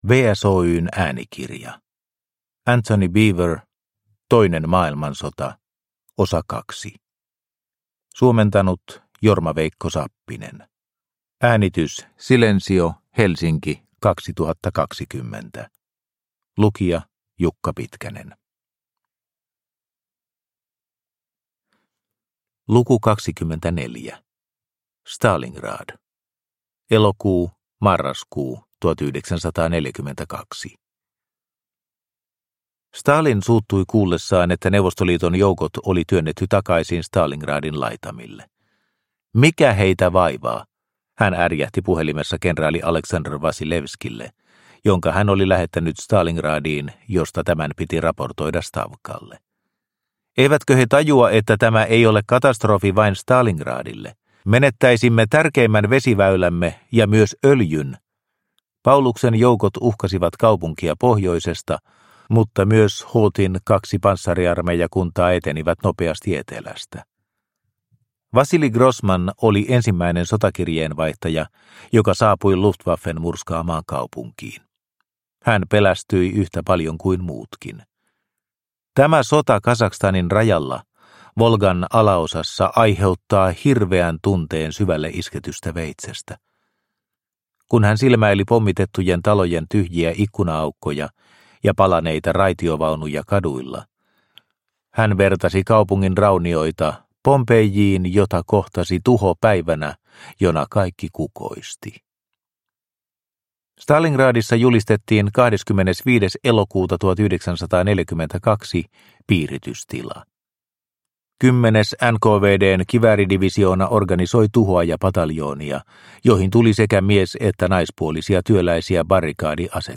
Toinen maailmansota, osa 2 – Ljudbok – Laddas ner